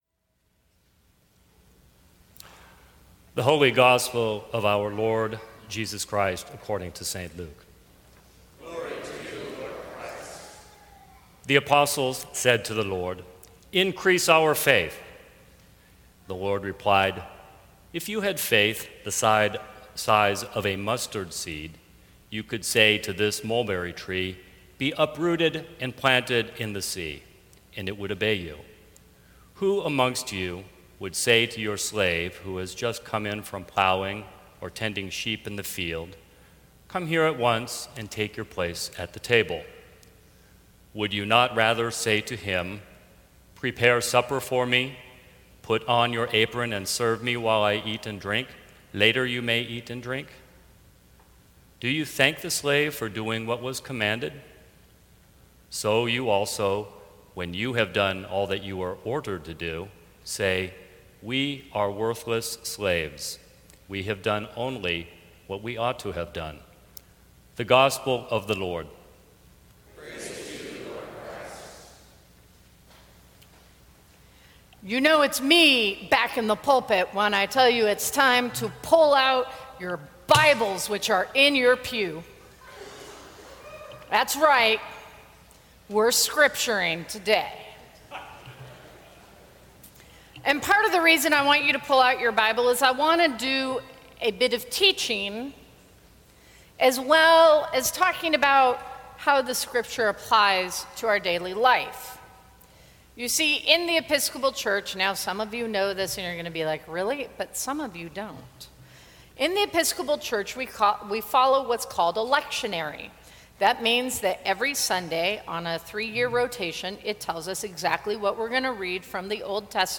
Sermons from St. Cross Episcopal Church Faith: Moving trees is the easy part!